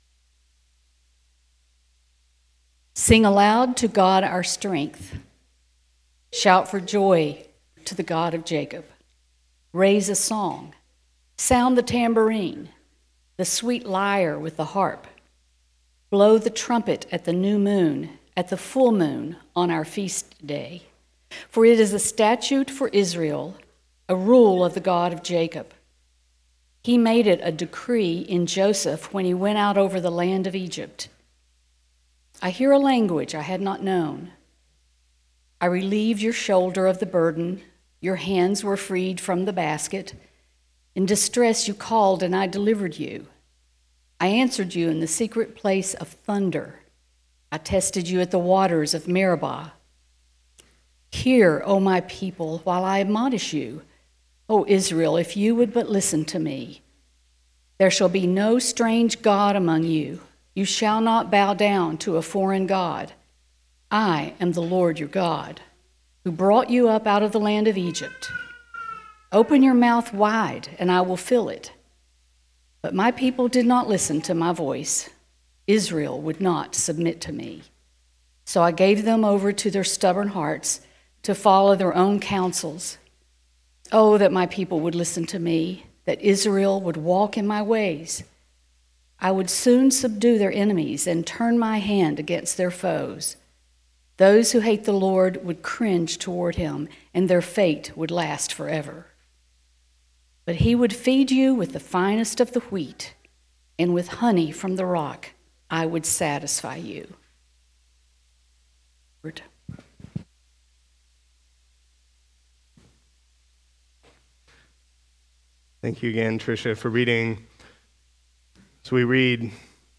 Sermons Archive - Christ Our Redeemer